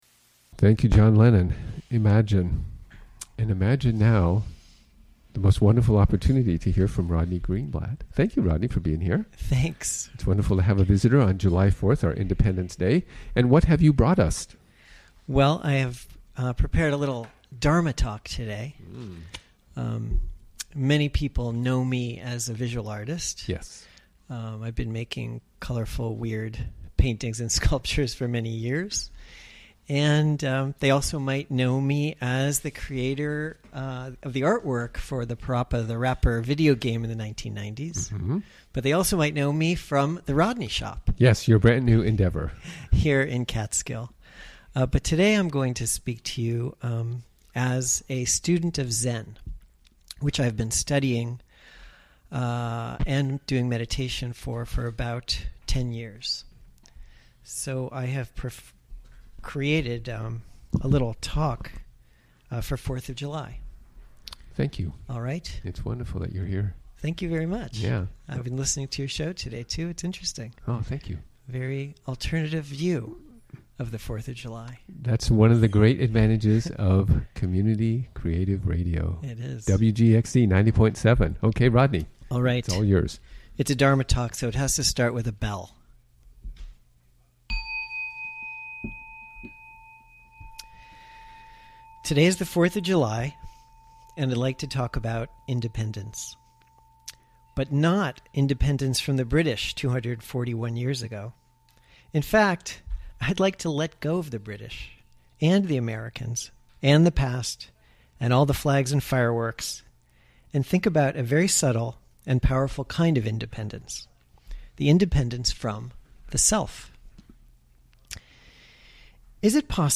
Recorded during the WGXC Afternoon Show of Tuesday, July 4, 2017.